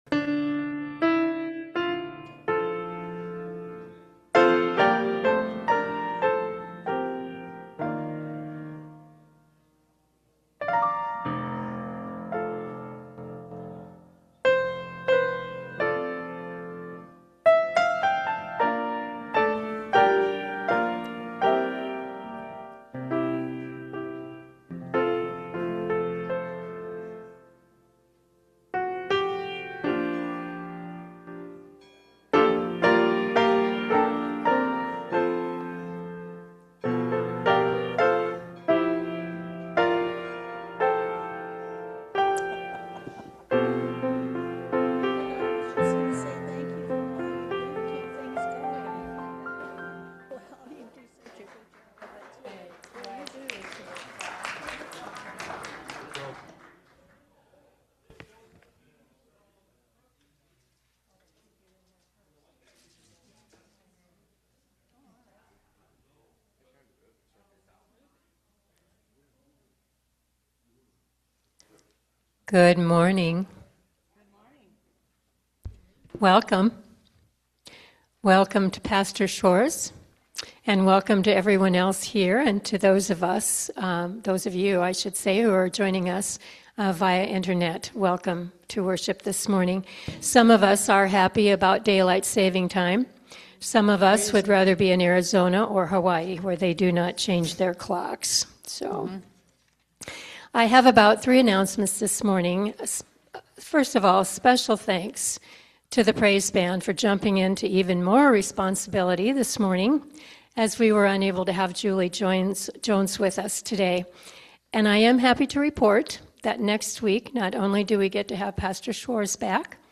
Worship-March-8-2026-Voice-Only.mp3